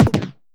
player-kicked.wav